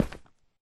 step / stone1